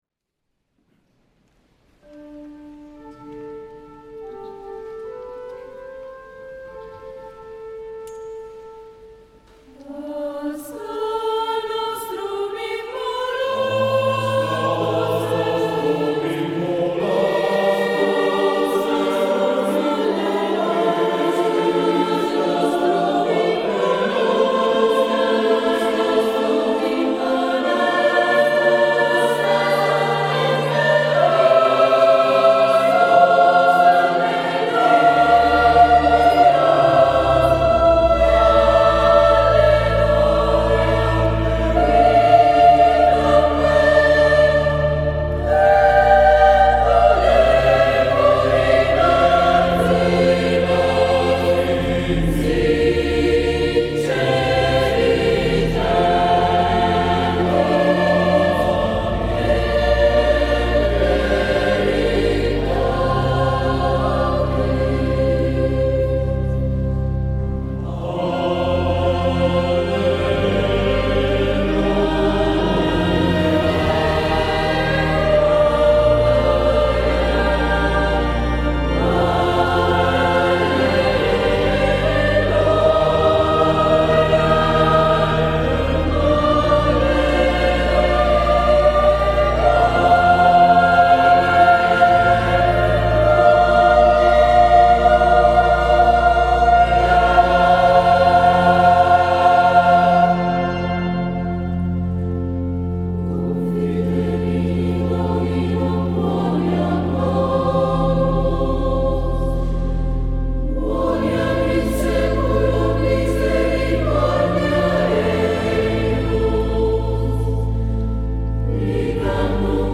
Sabato 05 maggio 2012 la corale ha eseguito la prima edizione del Concerto di S. Eurosia, in collaborazione con l'orchestra "L'Incanto Armonico" di Pisogne (BS).